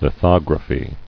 [li·thog·ra·phy]